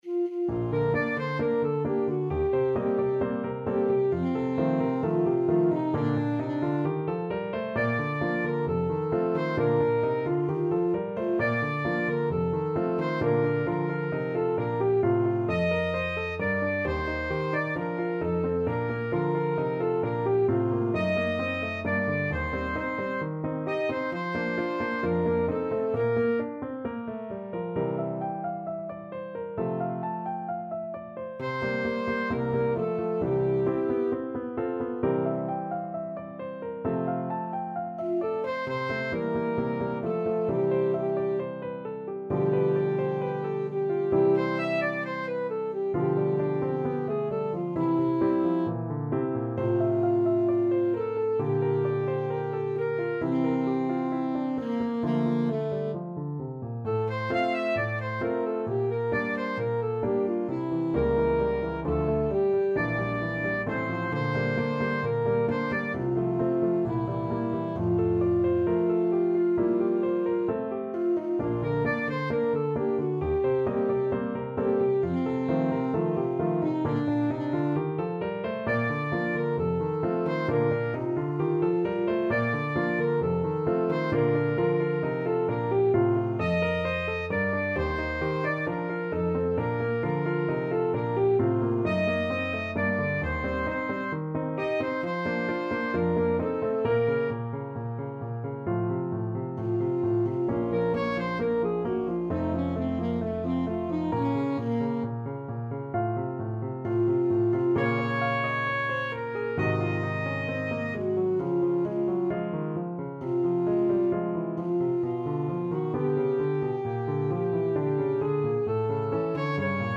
Classical Mozart, Wolfgang Amadeus Mi tradi quell'alma ingrata from Don Giovanni Alto Saxophone version
Alto Saxophone
Bb major (Sounding Pitch) G major (Alto Saxophone in Eb) (View more Bb major Music for Saxophone )
=132 Allegro assai (View more music marked Allegro)
Classical (View more Classical Saxophone Music)